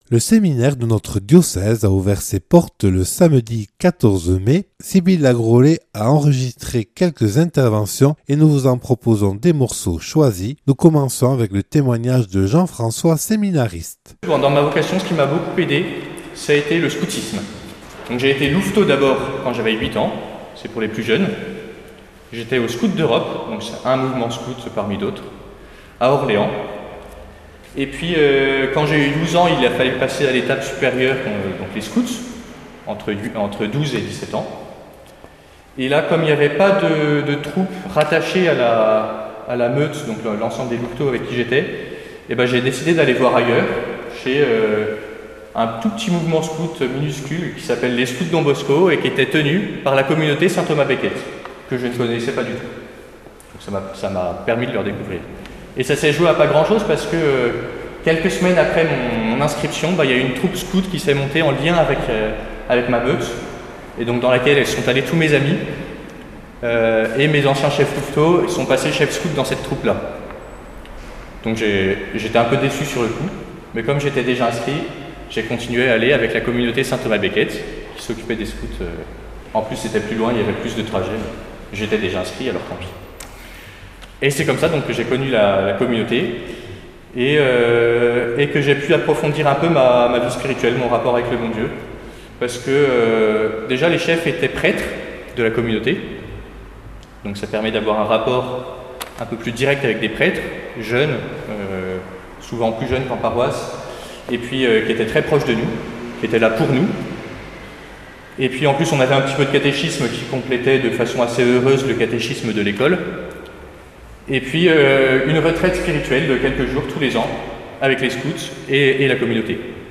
Retour sur les portes ouvertes du séminaire diocésain le 14 mai.